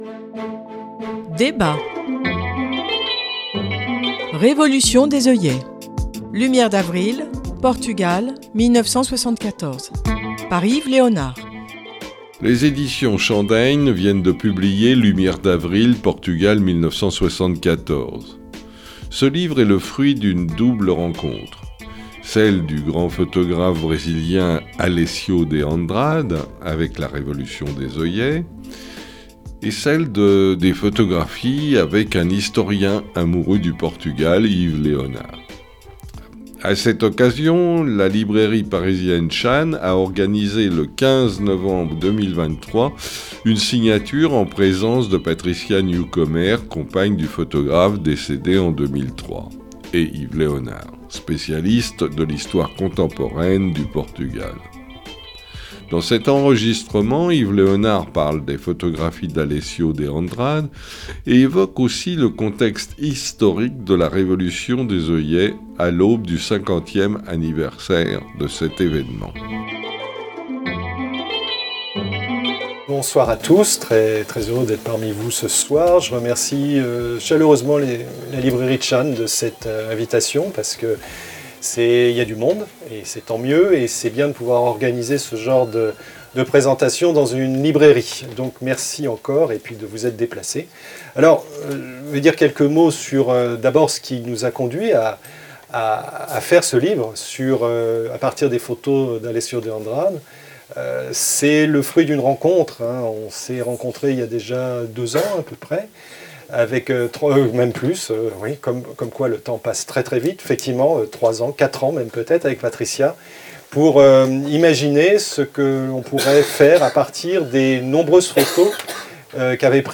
la librairie parisienne Tschann a organisé le 15 novembre 2023 une signature